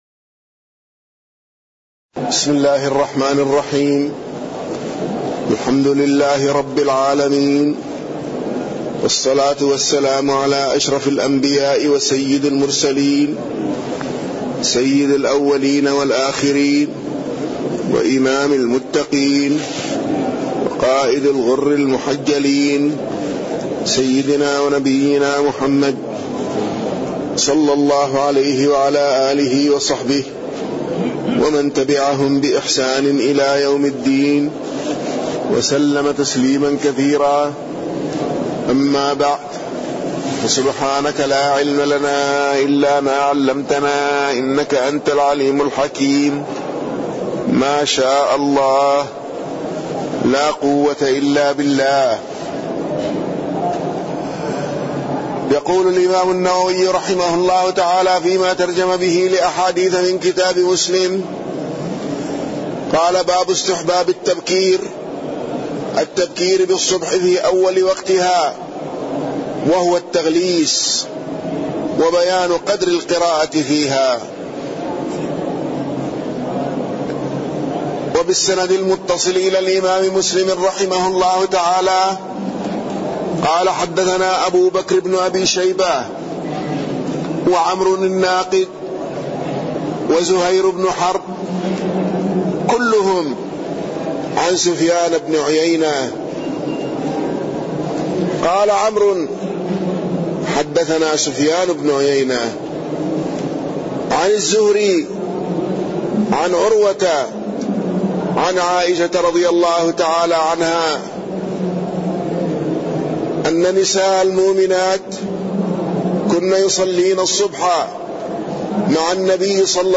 تاريخ النشر ٢ صفر ١٤٣٠ هـ المكان: المسجد النبوي الشيخ